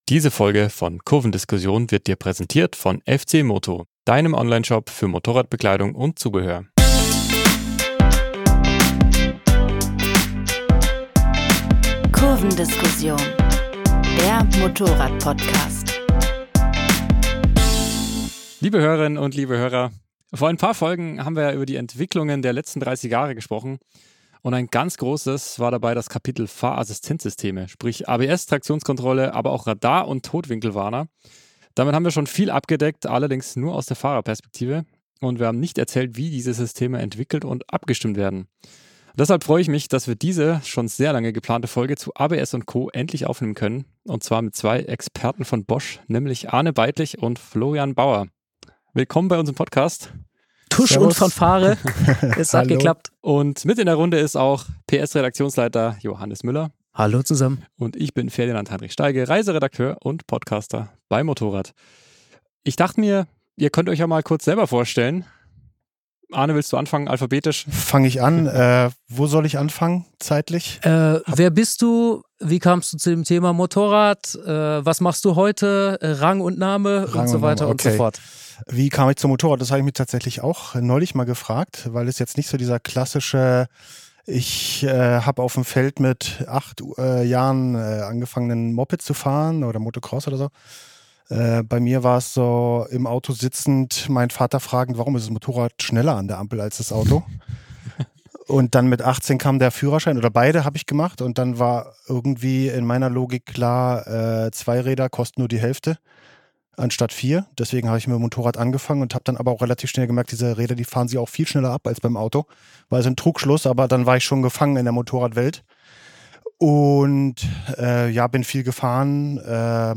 Für uns ein Grund, dieses große Thema mit zwei Experten aus der Branche zu besprechen.